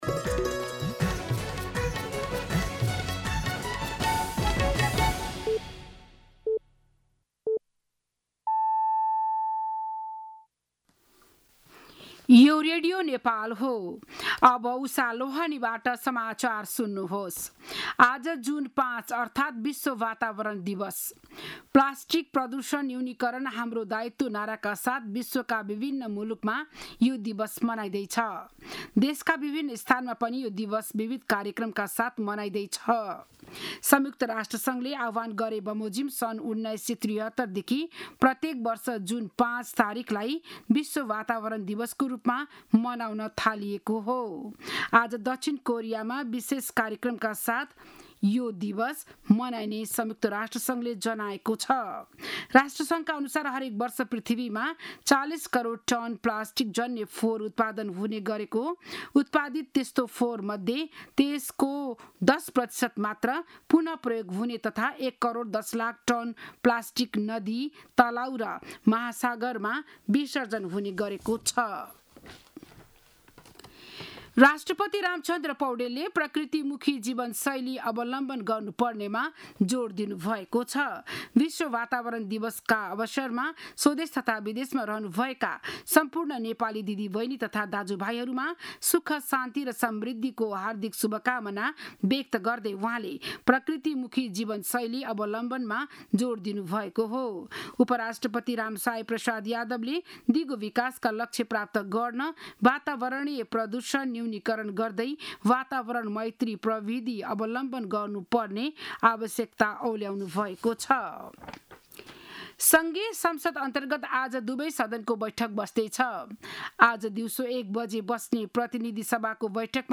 बिहान ११ बजेको नेपाली समाचार : २२ जेठ , २०८२